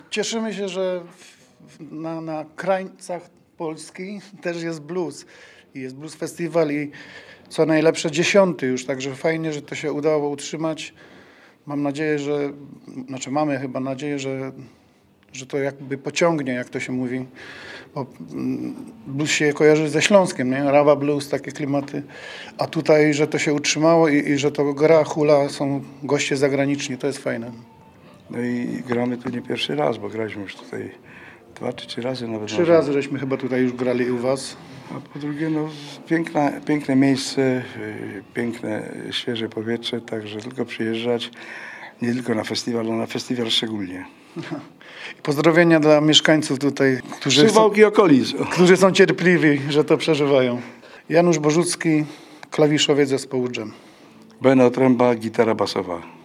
Koncert Dżemu zakończył czterodniowy, jubileuszowy 10. Suwałki Blues Festival. Legendarny zespół wystąpił w niedzielę (08.07) na scenie na ulicy Kościuszki w centrum miasta. Muzycy gratulowali Suwałkom festiwalu i wspominali swój występ sprzed pięciu lat, oczywiście na Suwałki Blues Festival.